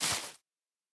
Minecraft Version Minecraft Version 1.21.5 Latest Release | Latest Snapshot 1.21.5 / assets / minecraft / sounds / block / sponge / step6.ogg Compare With Compare With Latest Release | Latest Snapshot